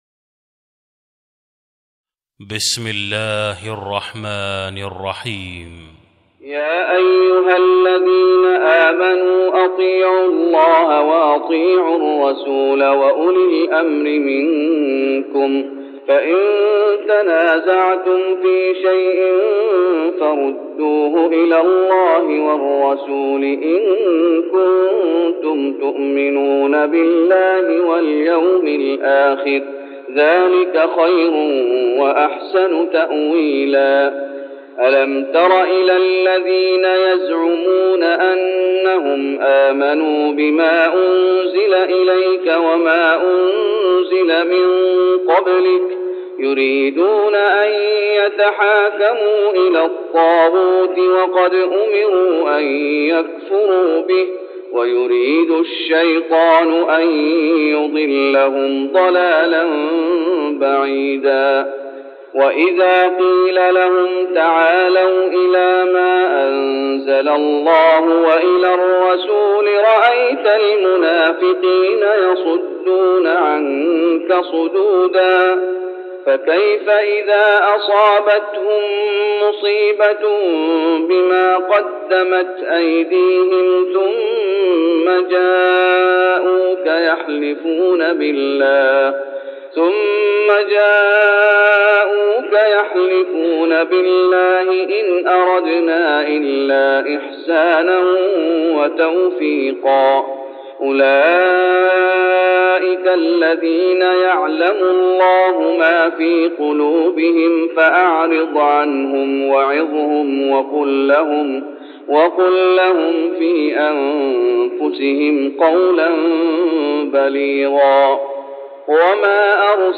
تهجد رمضان 1410هـ من سورة النساء (59-78) Tahajjud Ramadan 1410H from Surah An-Nisaa > تراويح الشيخ محمد أيوب بالنبوي عام 1410 🕌 > التراويح - تلاوات الحرمين